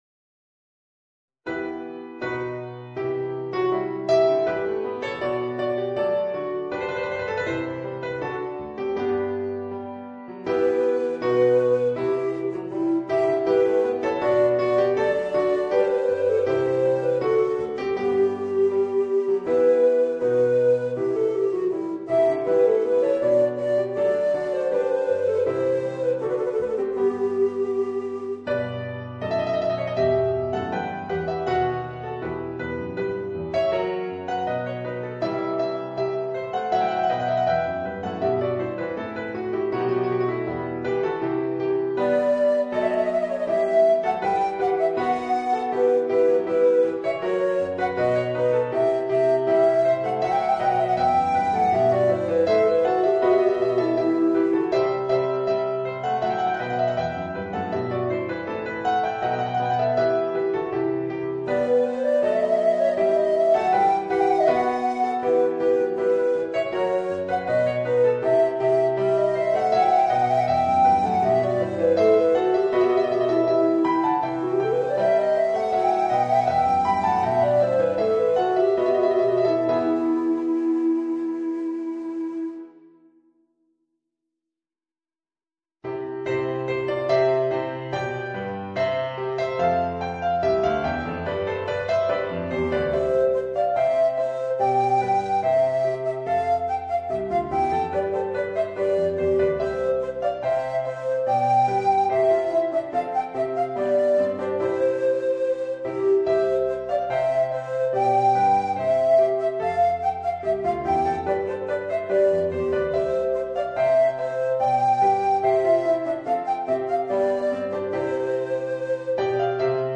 Tenor Recorder and Organ